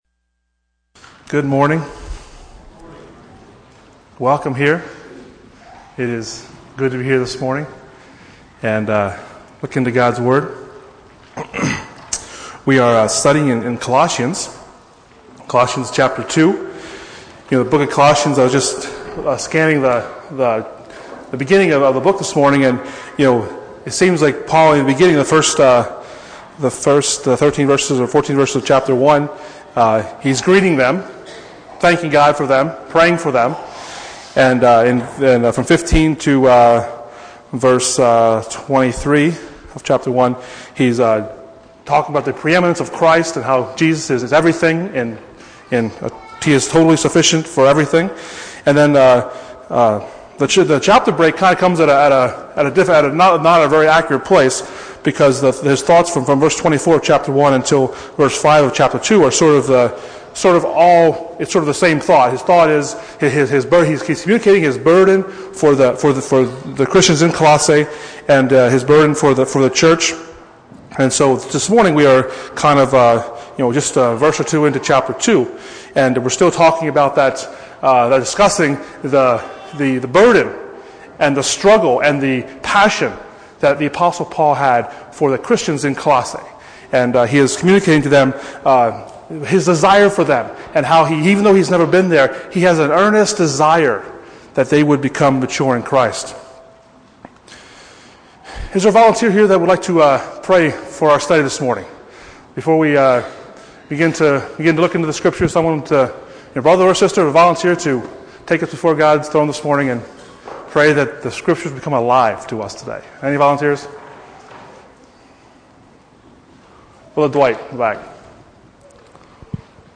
Passage: Colossians 2:1-5 Service Type: Sunday Morning